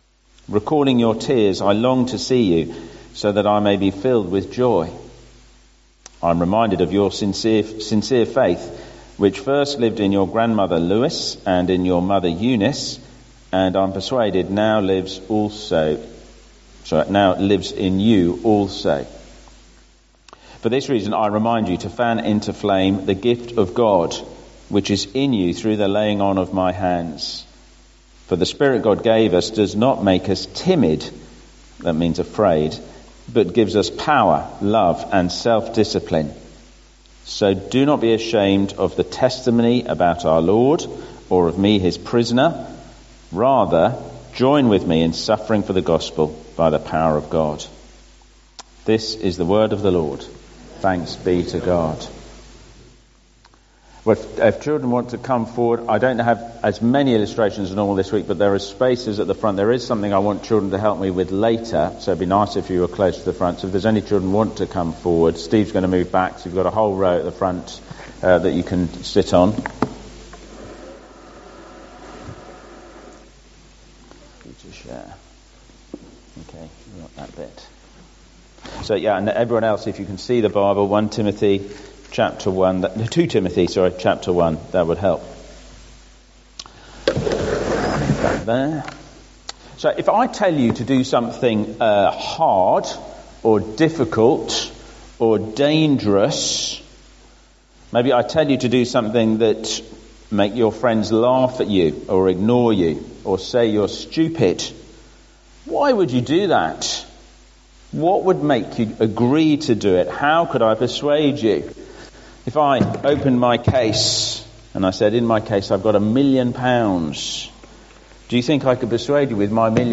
Sunday 2nd June 2024 Dagenham Parish Church Morning Service